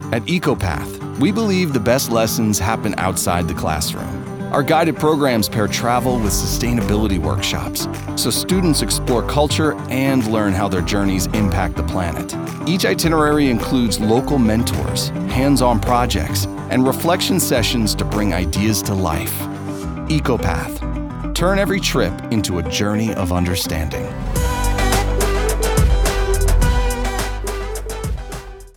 Warm · Friendly · Informative
Natural, easygoing narration for explainer videos, sustainability brands, and corporate storytelling.